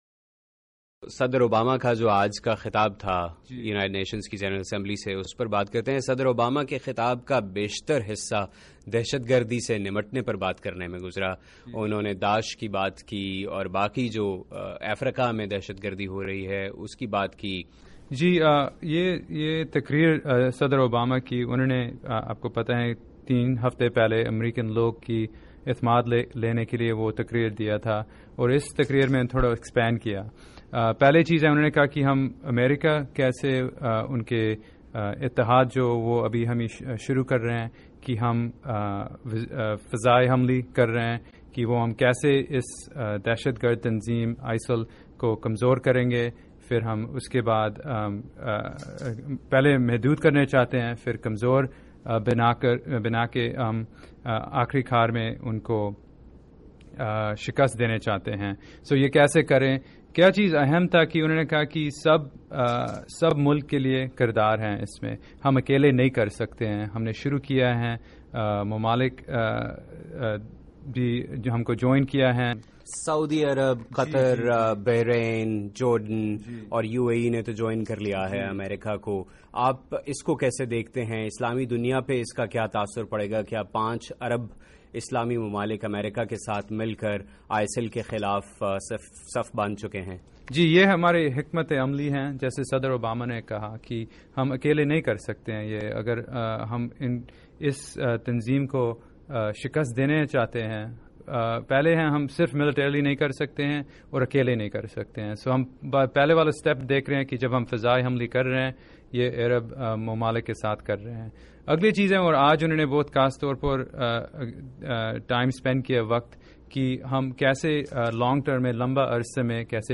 خصوصی بات چیت